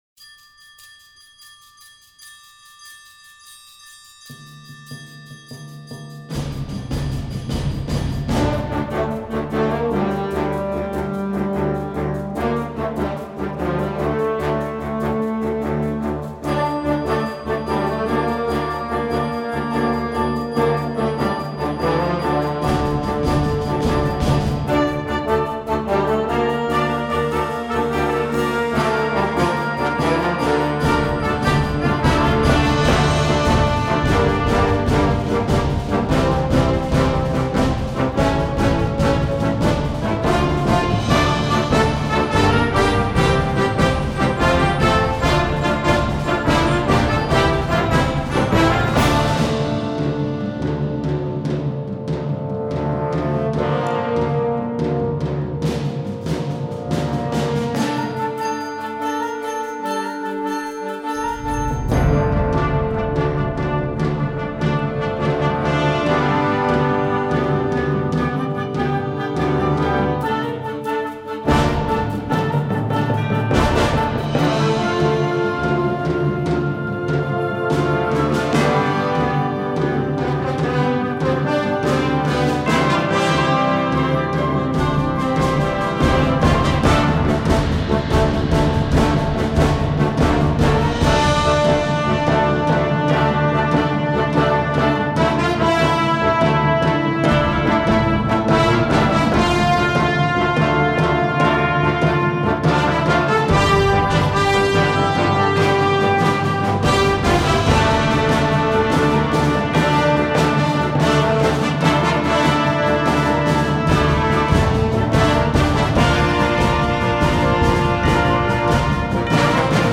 Gattung: Unterhaltungsmusik für Jugendblasorchester
Besetzung: Blasorchester